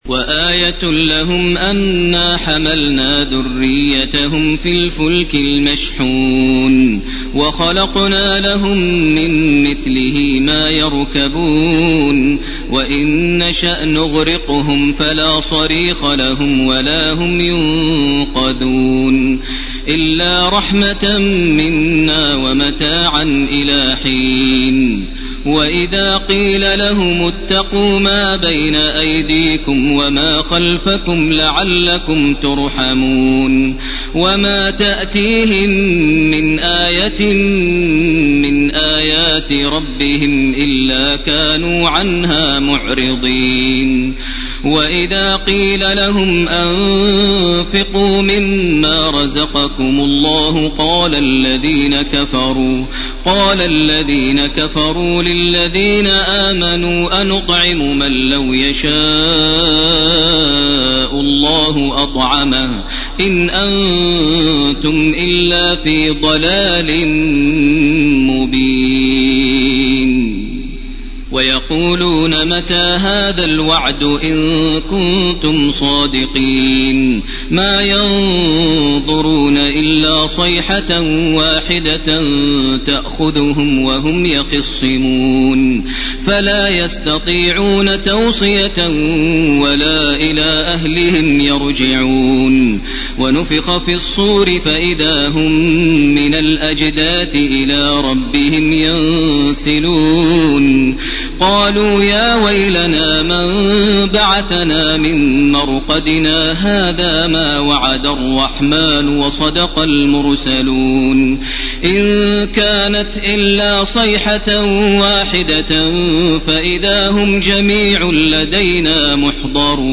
2010-09-01 > Taraweh 1431 > Taraweeh - Maher Almuaiqly Recitations